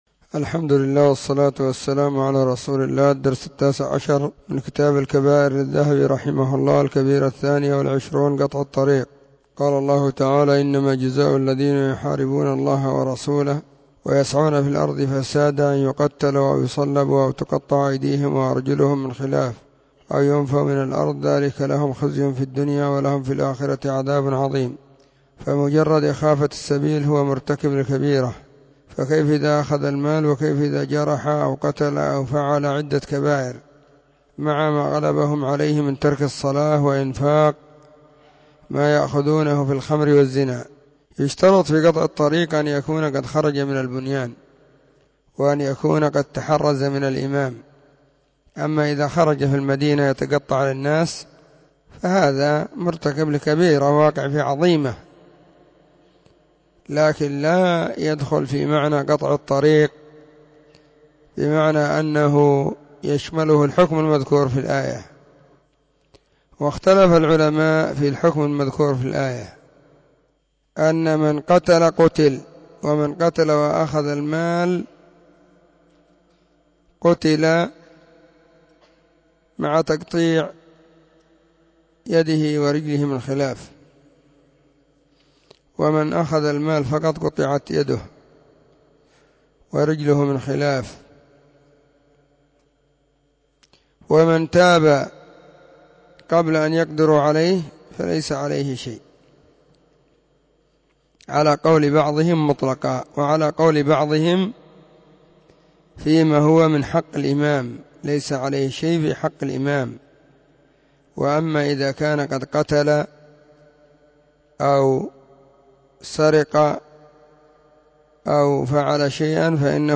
📖كتاب الكبائر 📀 الدرس :-19
🕐 [بين مغرب وعشاء – الدرس الثاني]